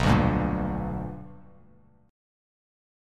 BmM7#5 chord